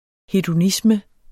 Udtale [ hedoˈnismə ]